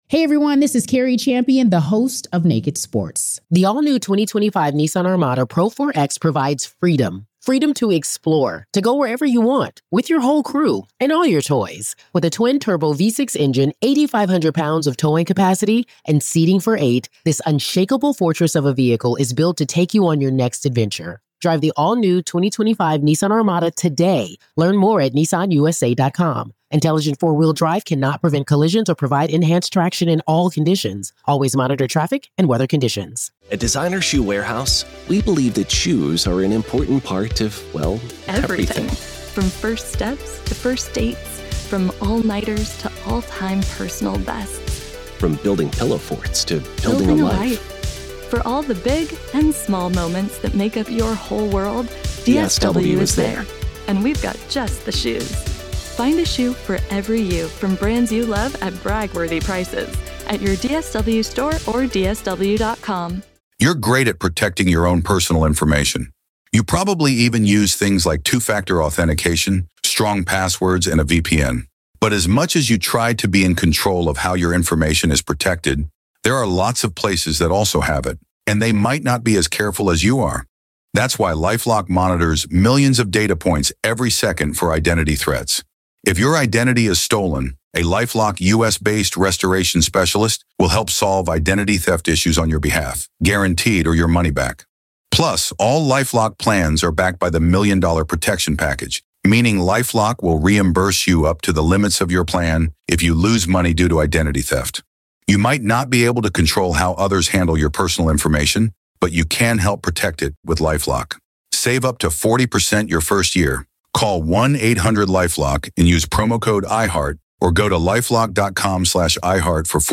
Airing Friday July 19th on Banfield on NewsNation.